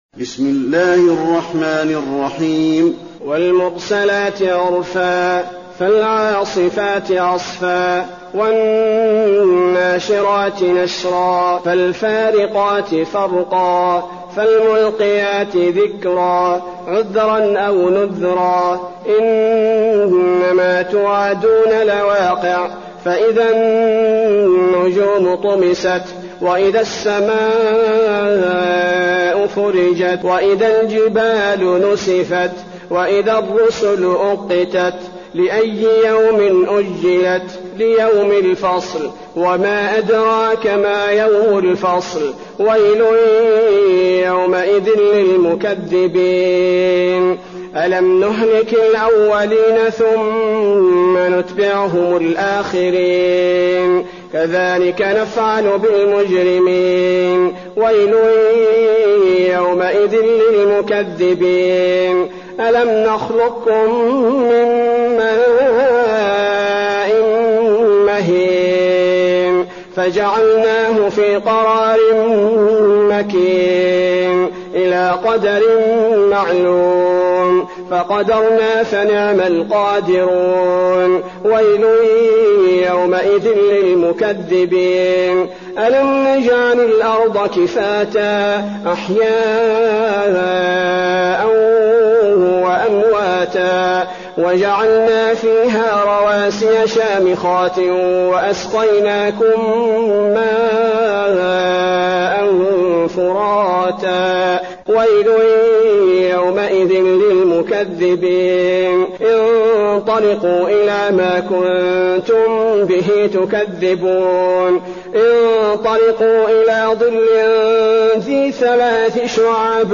المكان: المسجد النبوي المرسلات